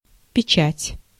Ääntäminen
IPA : /siːl/